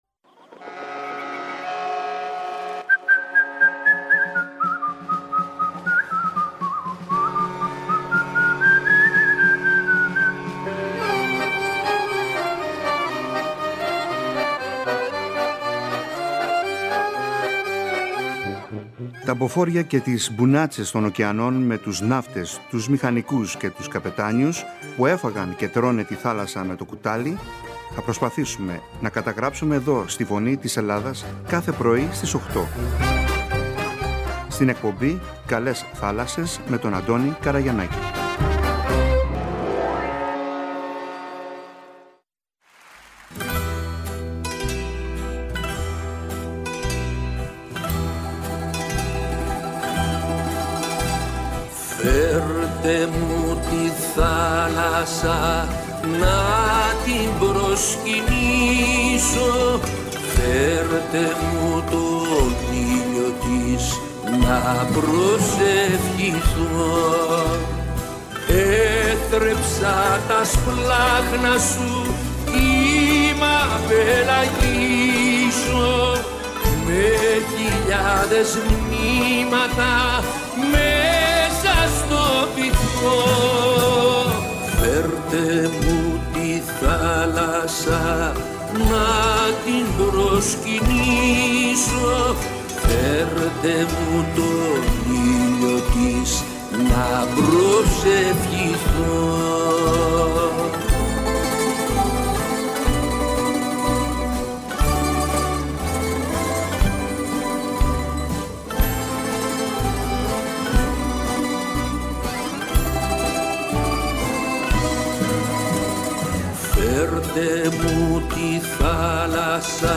Κατά τη διάρκεια των ΠΟΣΕΙΔΩΝΙΩΝ 2024 επισκέφτηκε το περίπτερο-στούντιο μας και μοιράστηκε μαζί μας σκέψεις και προβληματισμούς γύρω από την Ελληνική Εμπορική Ναυτιλία του σήμερα και του αύριο σε μια πολύ ενδιαφέρουσα συζήτηση-συνέντευξη.